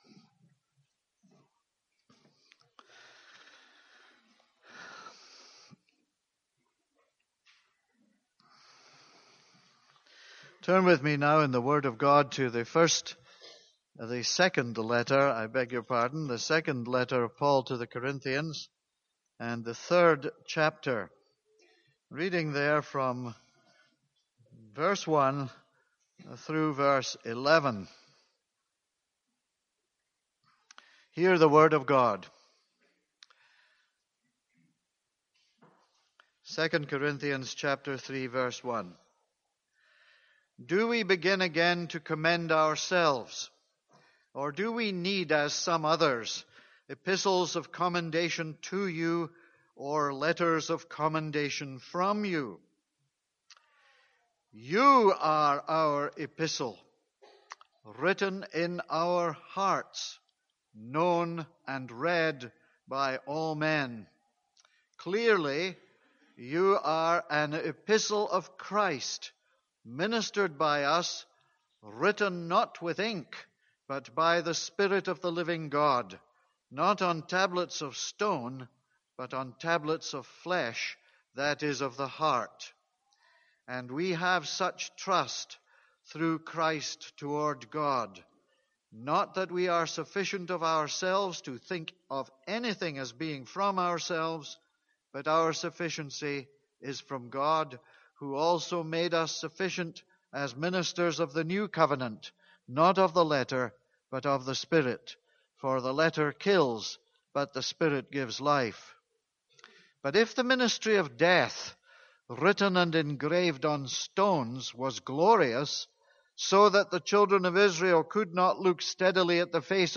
This is a sermon on 2 Corinthians 3:7-11.